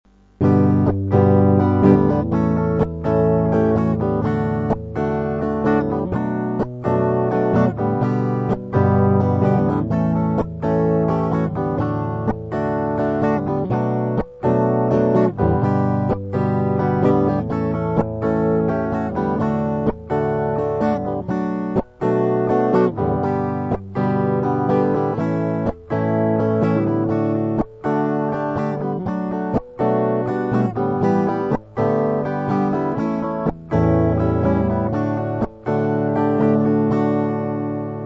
mp3 - припев